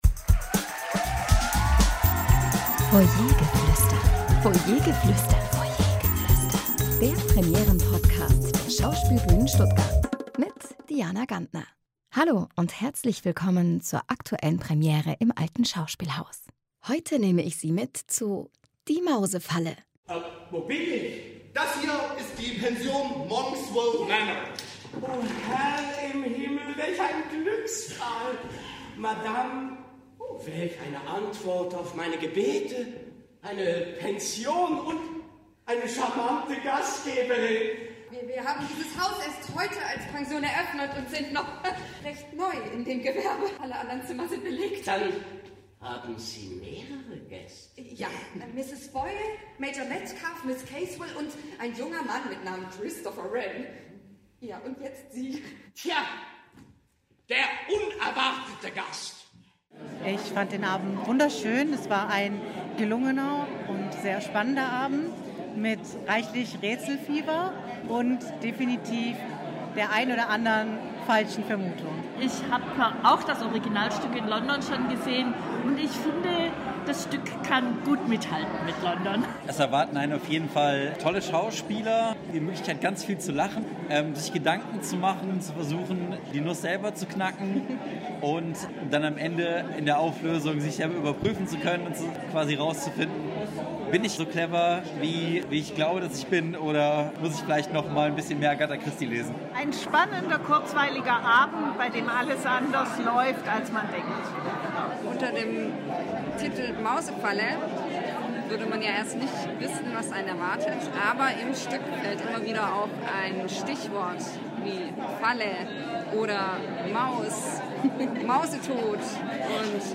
Zuschauerstimmen zur Premiere von “Die Mausefalle”